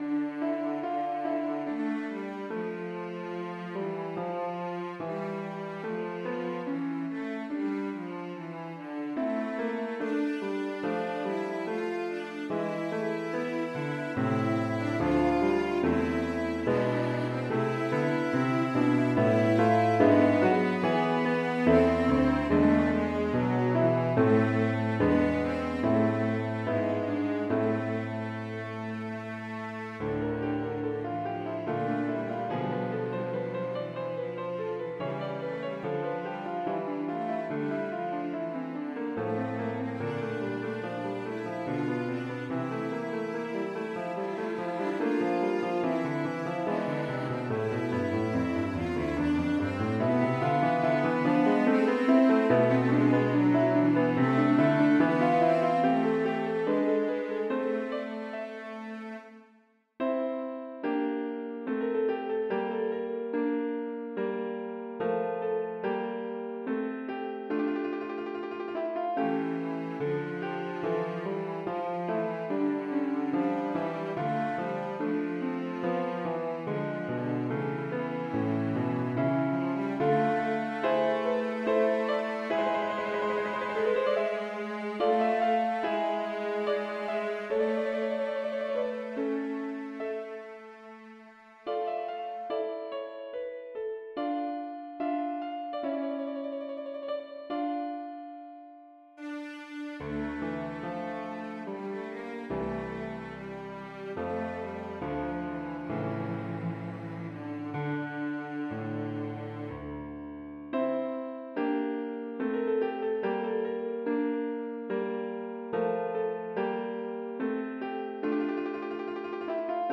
Lydfilene er digitalt produsert i noteprogrammet og er bare illustrasjoner.
Her er en mer krevende og modernisert versjon av en melodi fra Peter Bangs notebok.
- Bang 153c: Anshuc for cello og piano   Note